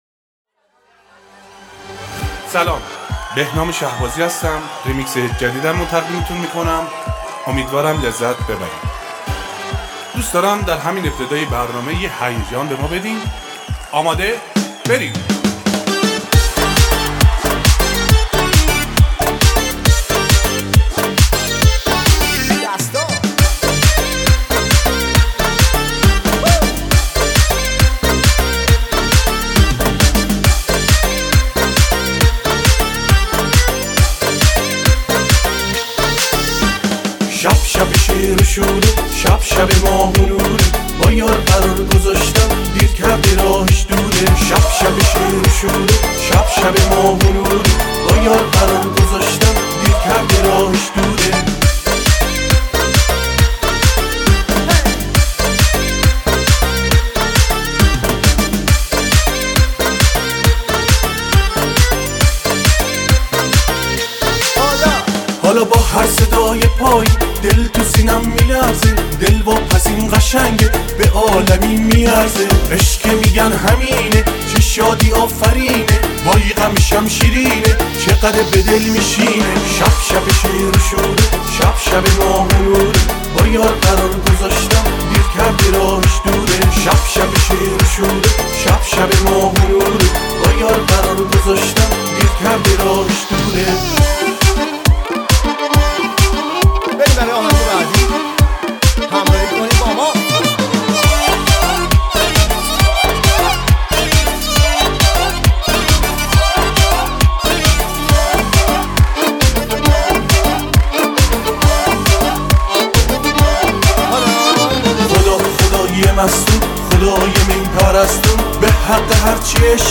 آهنگ شاد ارکستی آهنگ شاد جشنی